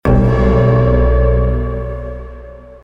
короткие
пугающие
страшные
жуткие